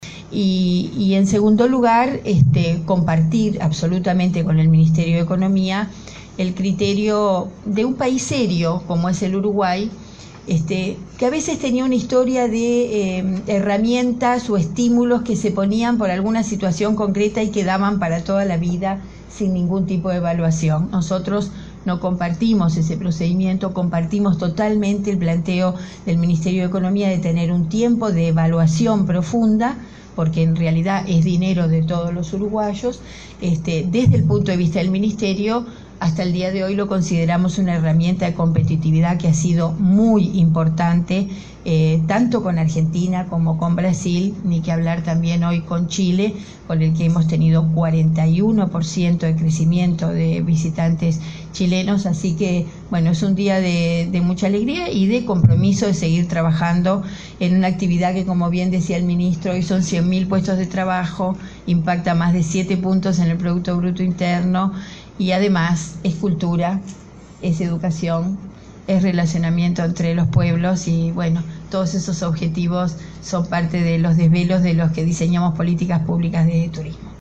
La ministra de Turismo, Liliam Kechichian, compartió las medidas adoptadas por Economía de extensión de los beneficios fiscales para turistas. Dijo que se trata de una herramienta de competitividad muy importante, tanto con Argentina como con Brasil y Chile, país que registra un incremento de turistas hacia el nuestro de 41%. Recordó que los 100 mil puestos de trabajo del turismo impactaron más de 7 puntos en el PIB.